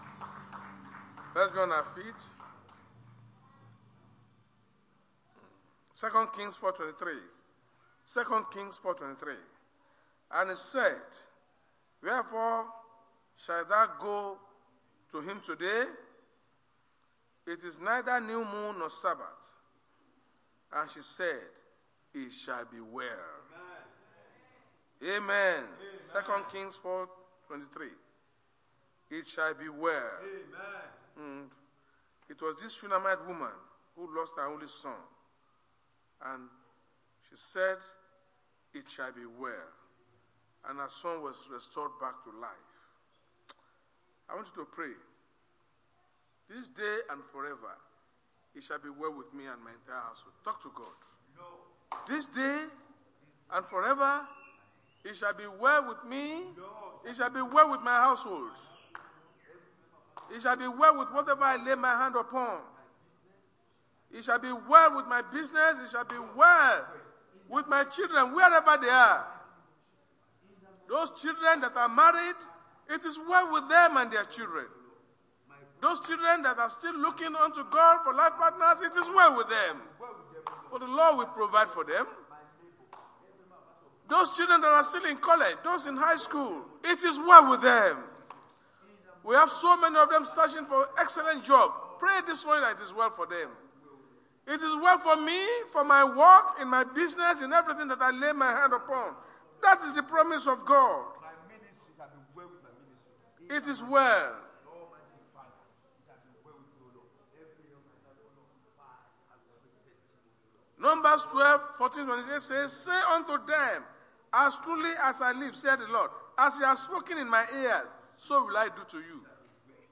RCCG House Of Glory Sunday Sermon: The Meaning Of Forgiveness
Service Type: Sunday Church Service